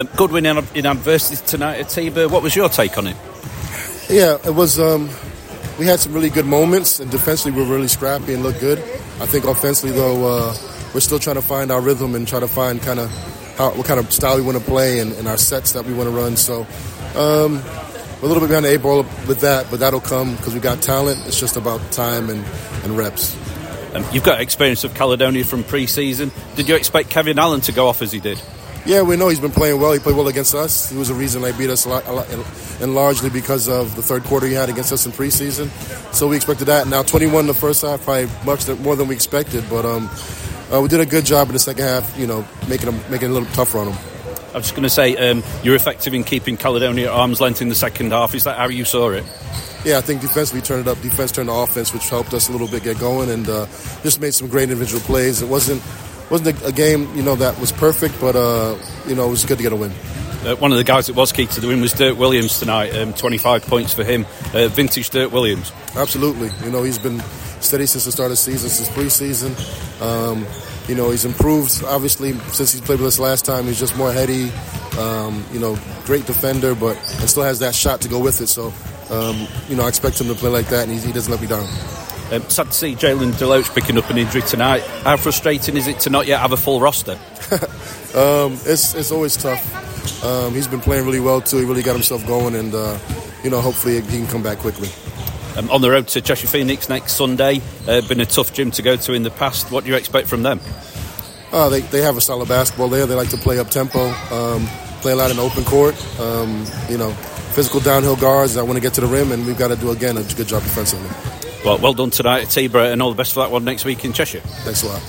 Sharks secure another good win – Interview